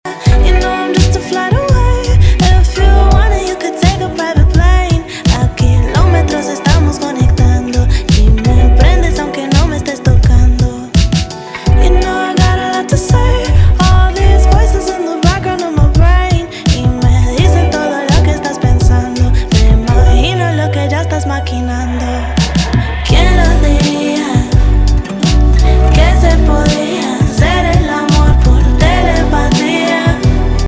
The song blends sultry R&B vibes with Latin influences